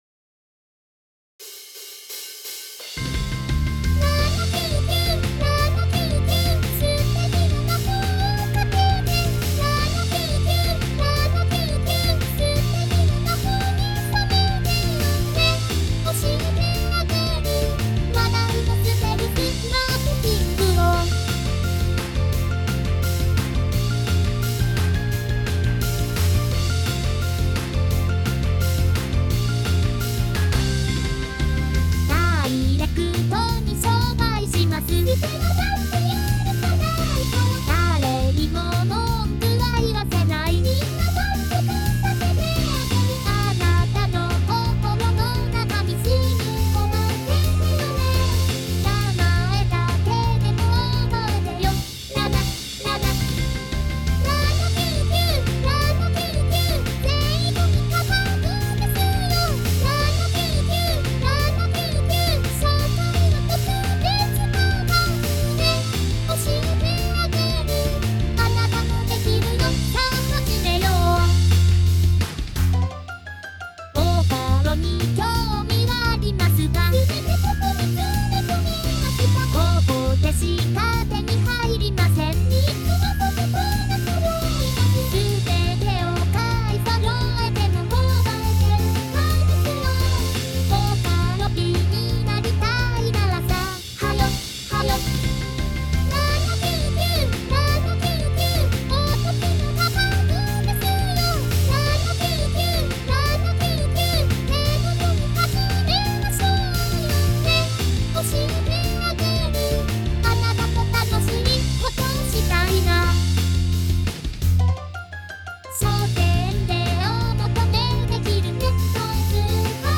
まあプラグインを何も使わず、適当にボリューム調整してみましたのがこれです。
※容量の問題で、MP3に圧縮していますので、音質が劣化してます。
好みの問題で、ベースをちょっと大きめに出しています。